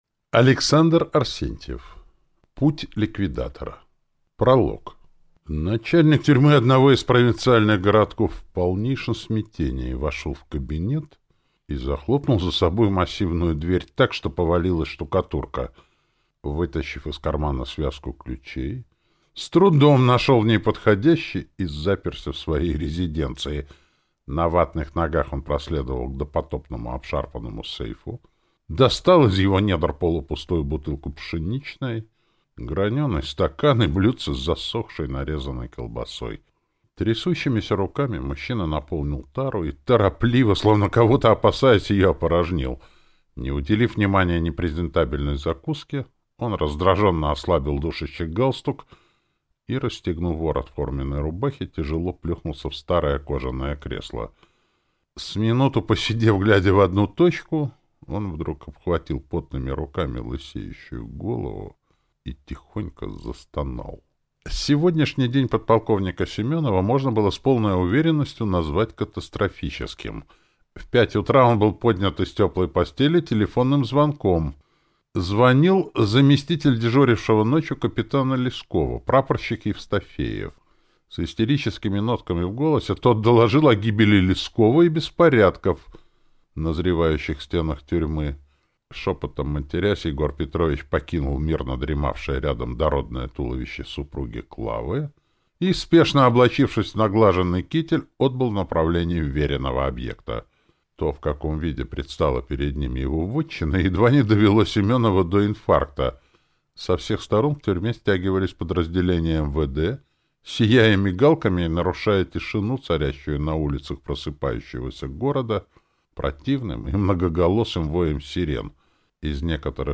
Аудиокнига Путь ликвидатора | Библиотека аудиокниг
Прослушать и бесплатно скачать фрагмент аудиокниги